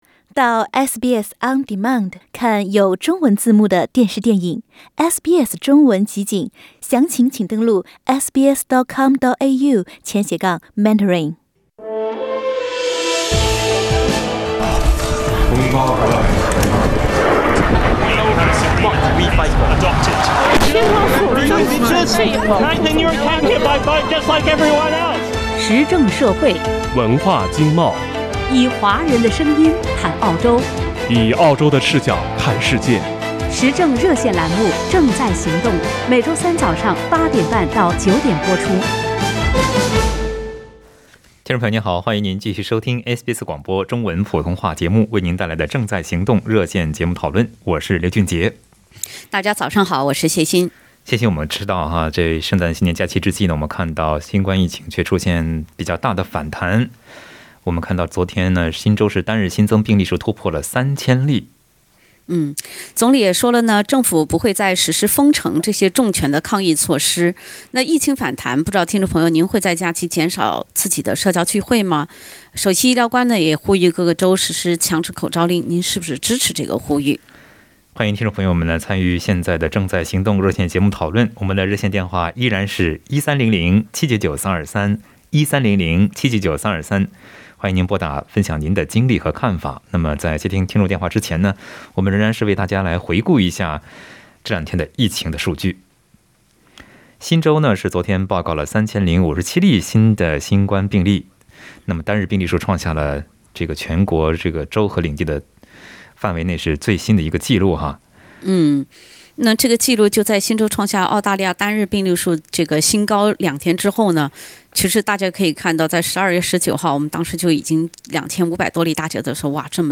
READ MORE 圣诞将至 快速抗原试剂盒需求激增 在本期《正在行动》热线节目中，听友们就疫情反弹和强制口罩令表达了看法。